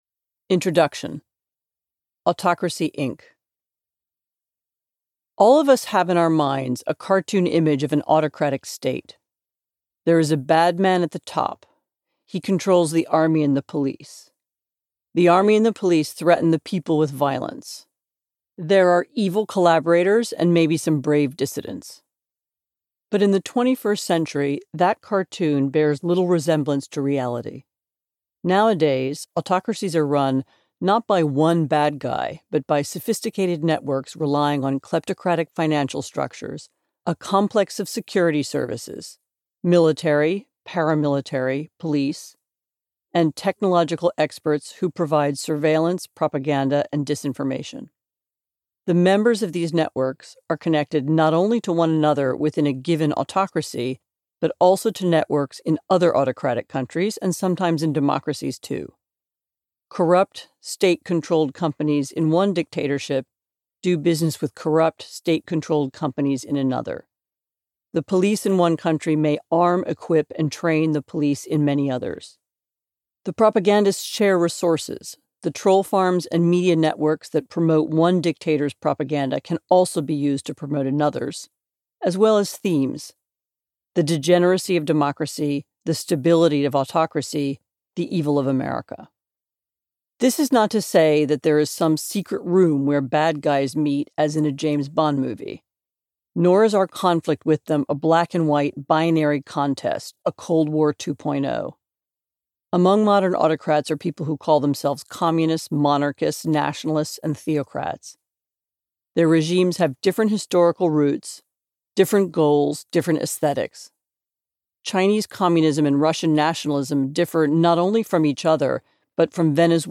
Audiobook sample